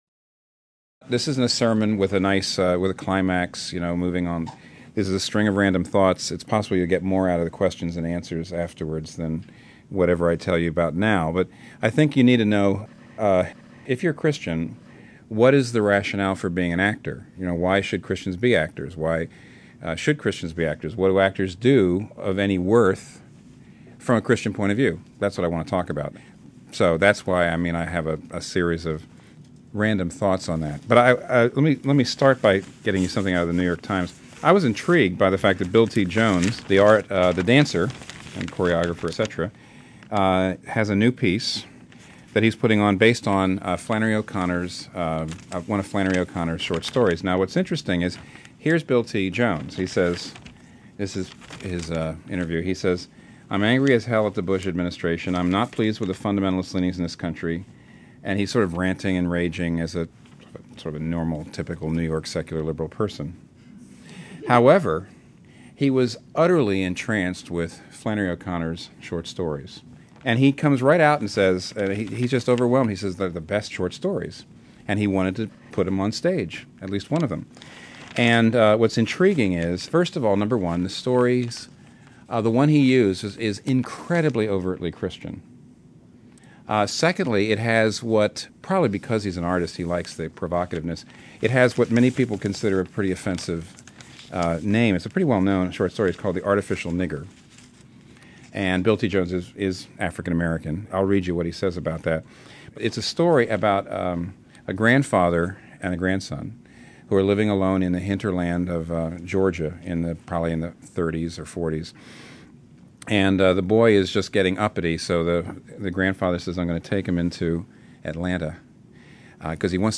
Tim Keller speaks to actors at Redeemer about the way God uses storytelling and the importance of that medium to God's kingdom.
Why_Tell_Stories_ (Lecture_&_Q&A).mp3